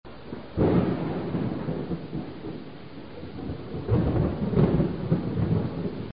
Thunderstorm
music_thunderstorm.CR6i0y9A.mp3